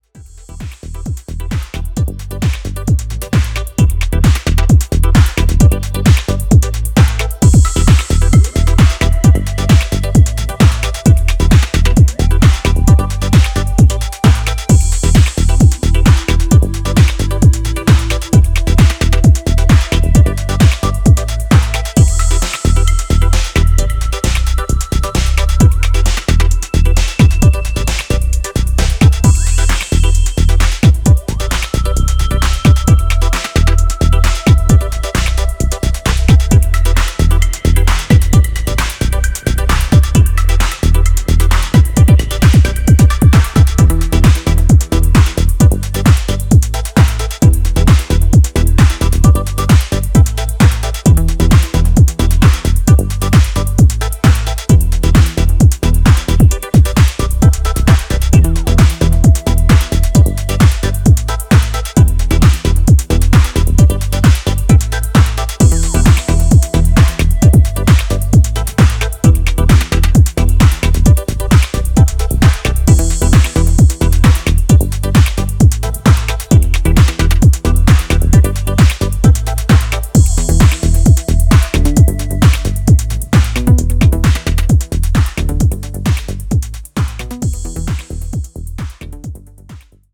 sultry sounds